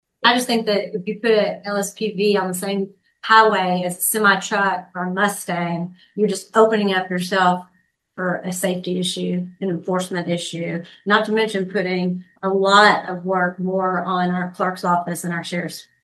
Hopkins County Fiscal Court is one step closer to allowing street-legal special purpose vehicles on certain local and state roads, following the approval of an ordinance on first reading at Tuesday morning’s meeting.